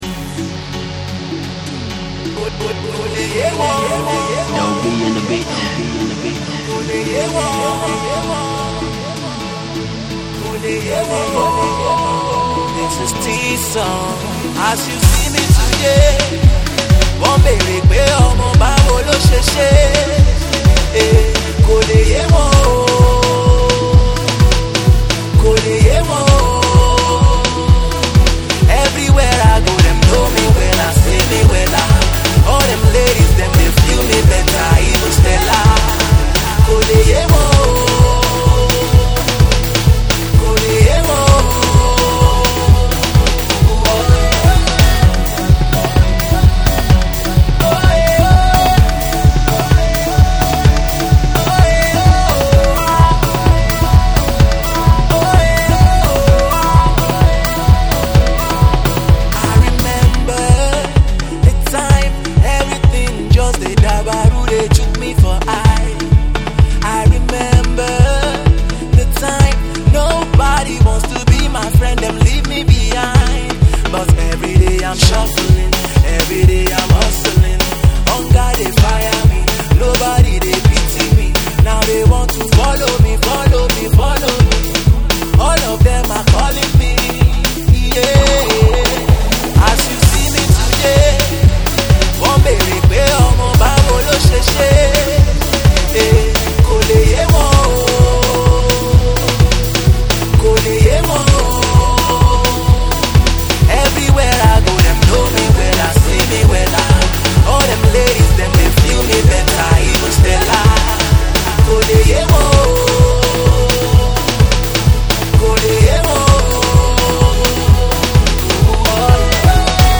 eclectic and earthy uptempo track